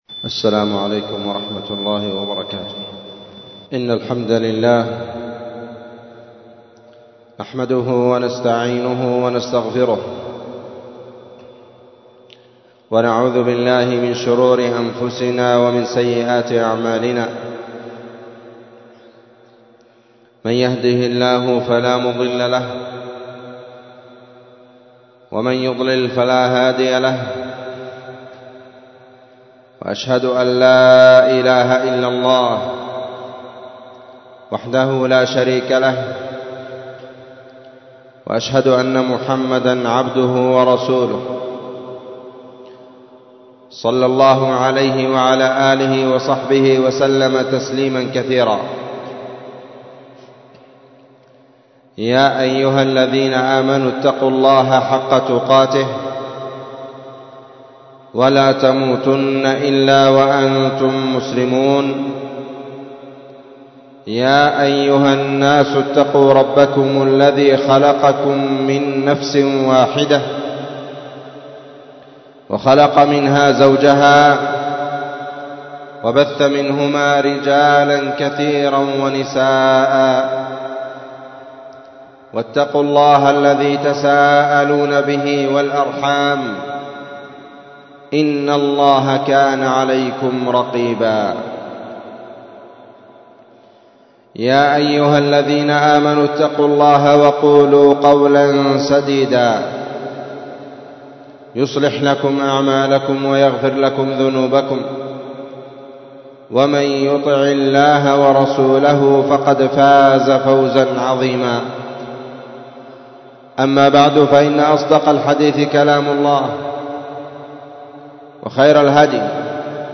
خطبة جمعة بعنوان مجاهدة النفس على الطاعات 9 رمضان 1444
والتي كانت في مسجد المجاهد- تعز